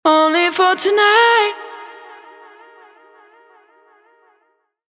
Her warm, deep, and soulful vocals bring profound emotion to everything they touch.
• 76 Unique vocal hooks – dry and wet.